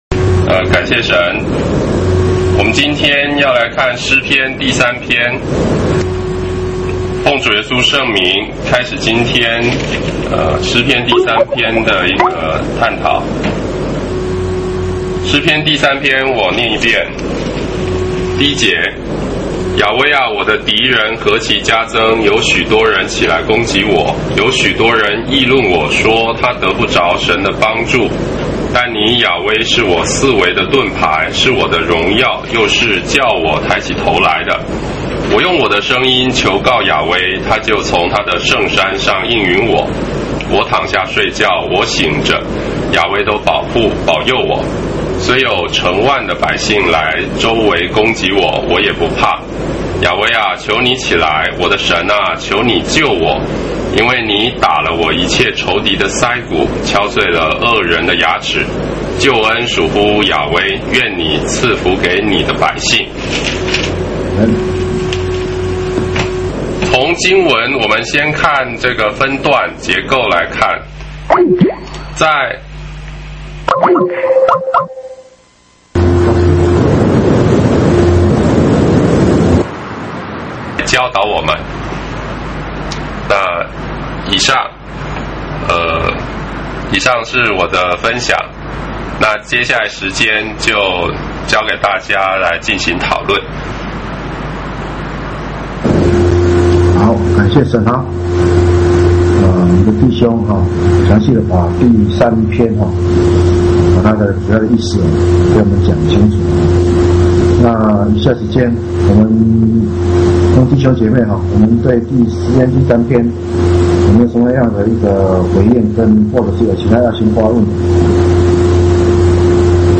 地點：北京、嘉義。